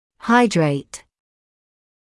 [‘haɪdreɪt], [haɪ’dreɪt][‘хайдрэйт], [хай’дрэйт]гидрат, гидроокись; гидратный; гидратизировать, гидратировать
hydrate.mp3